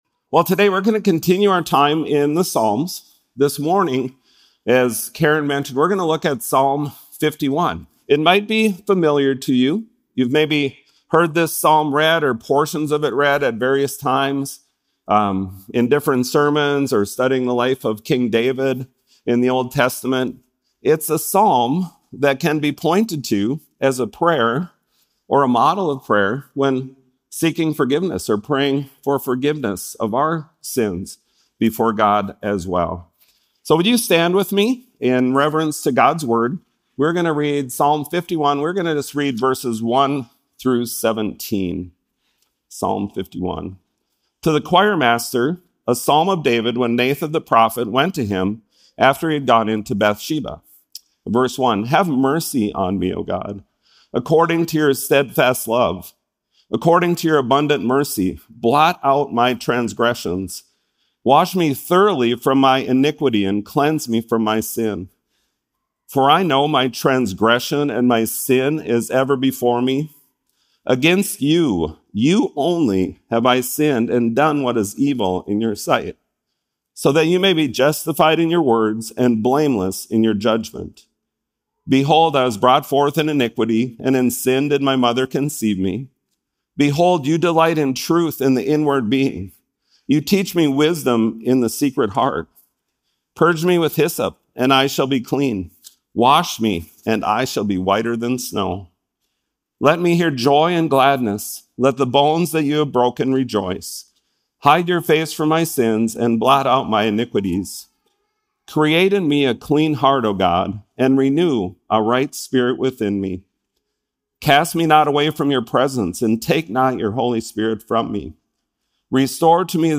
Palm Sunday Sermon